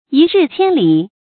注音：ㄧ ㄖㄧˋ ㄑㄧㄢ ㄌㄧˇ
一日千里的讀法